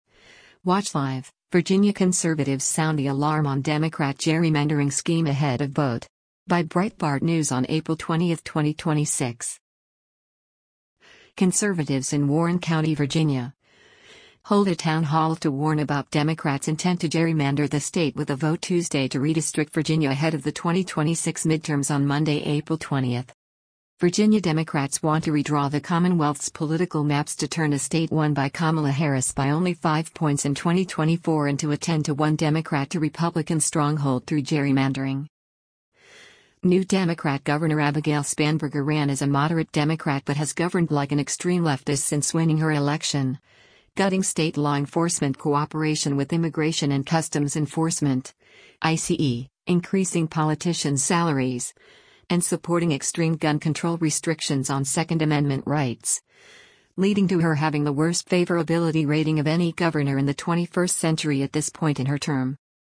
Conservatives in Warren County, Virginia, hold a town hall to warn about Democrats’ intent to gerrymander the state with a vote Tuesday to redistrict Virginia ahead of the 2026 midterms on Monday, April 20.